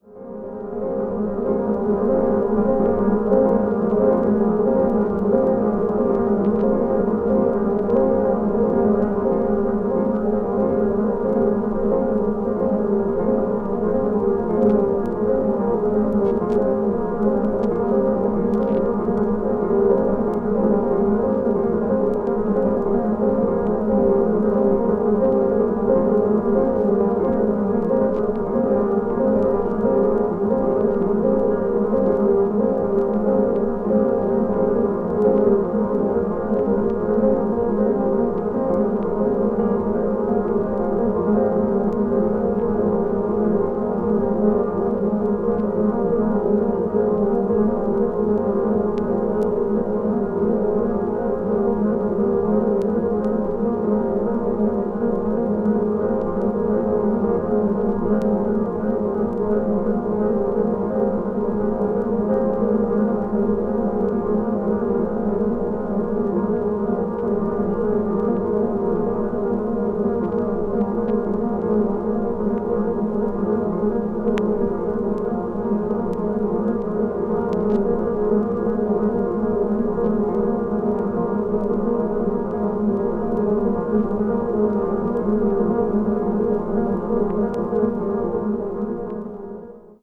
media : VG+/VG+(薄い擦れによるわずかなチリノイズが入る箇所あり,軽いプチノイズが入る箇所あり)